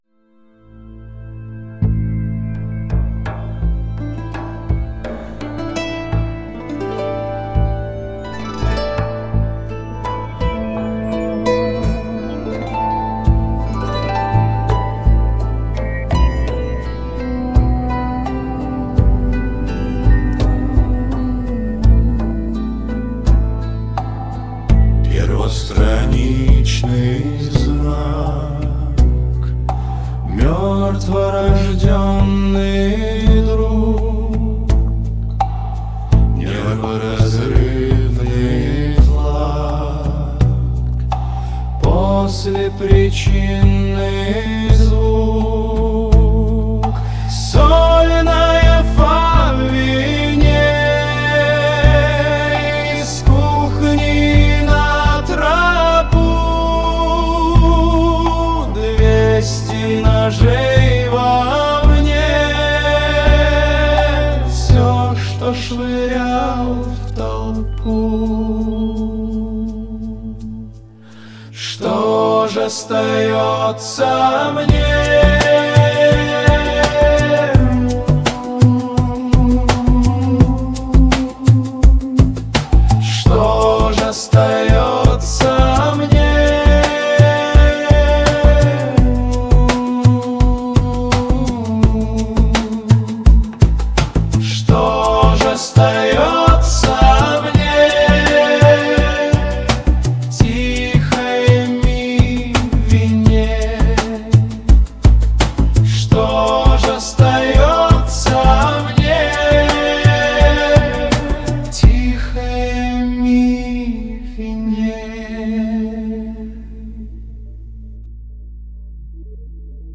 Один - мультиинструменталист, другой - бас и ударные.
Фолк